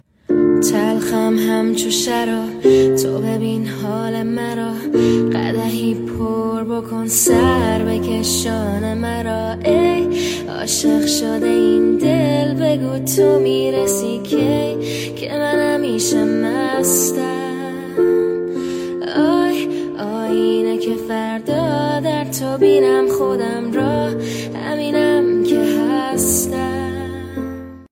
با صدای دختر